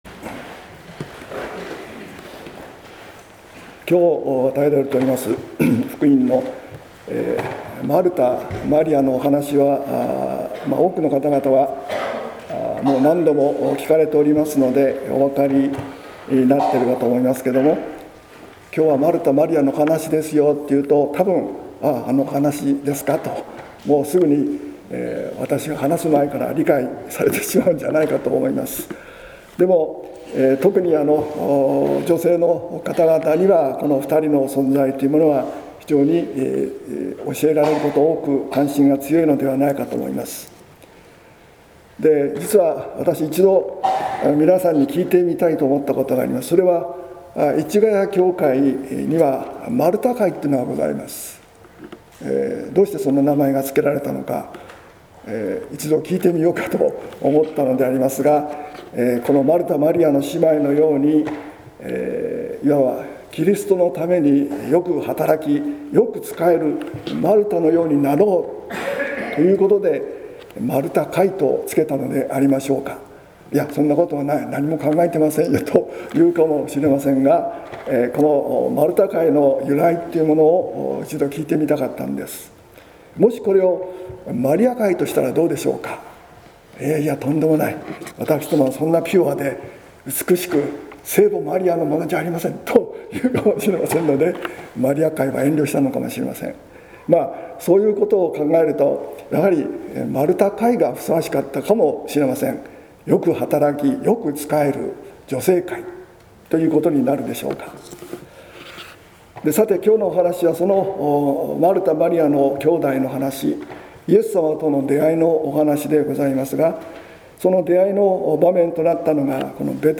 説教「イエスに仕えるマルタとマリア」（音声版） | 日本福音ルーテル市ヶ谷教会